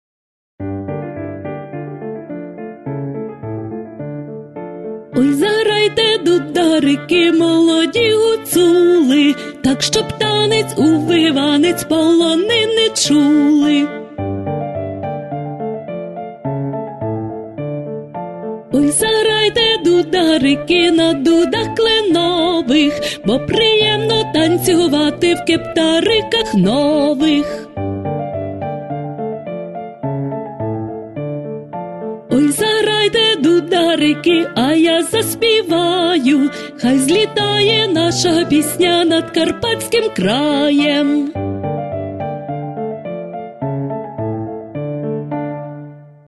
Демонстрація пісні
веселий настрій